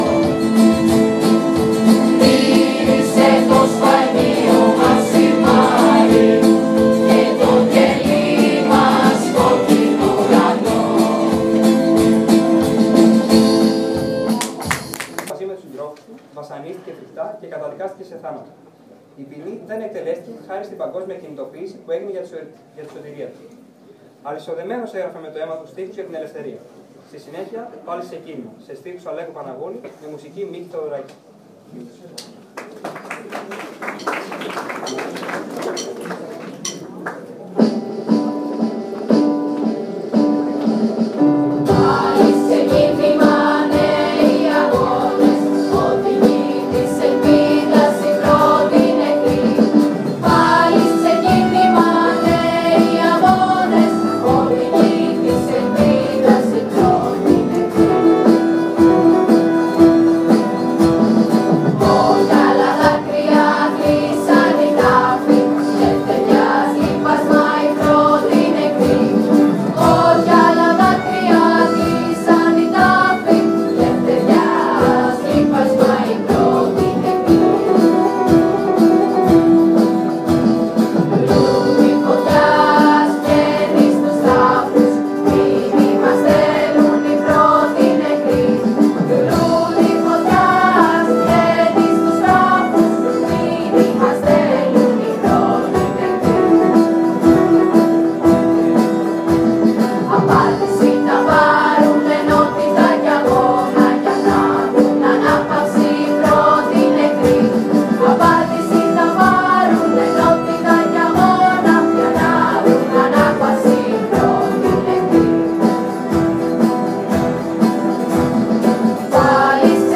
Μια υπέροχη γιορτή για την επέτειο του Πολυτεχνείου πραγματοποιήθηκε  στην αίθουσα εκδηλώσεων του σχολείου μας.
“Πάλης ξεκίνημα”, τραγούδι από την χορωδία.